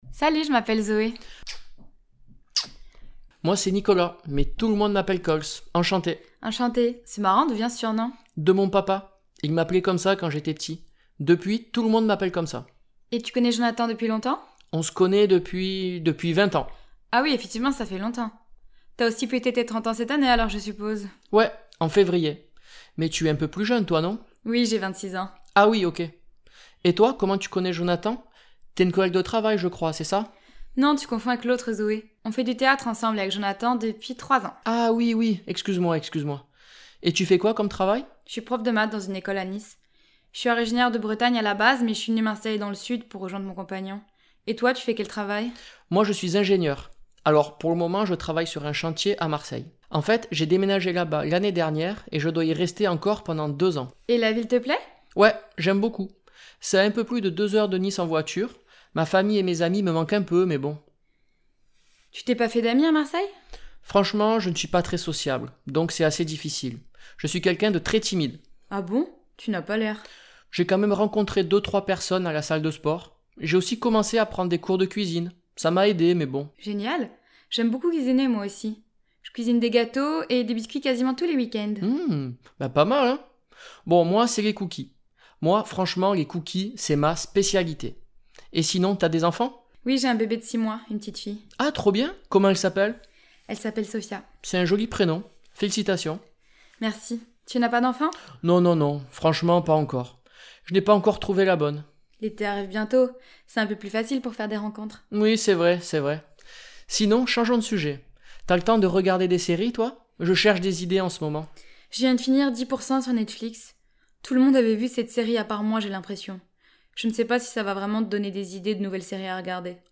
Parler de soi et apprendre à se présenter (son neutre)